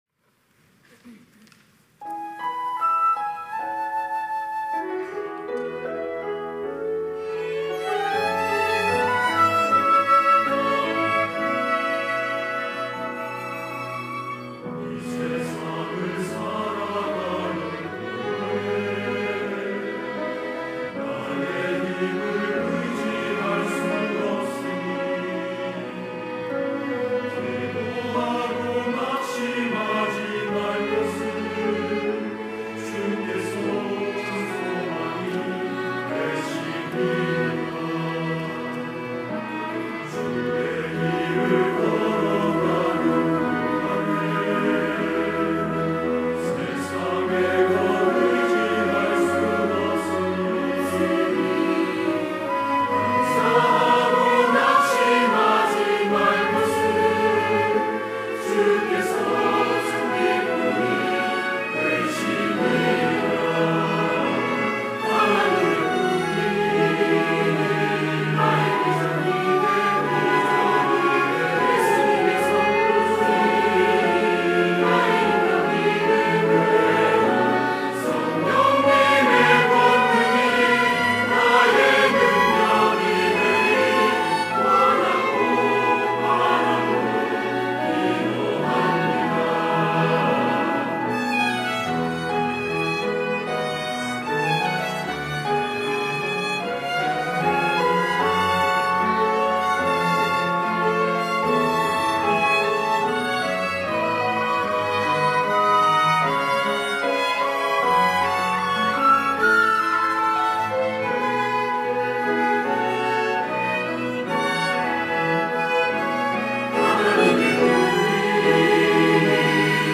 할렐루야(주일2부) - 원하고 바라고 기도합니다
찬양대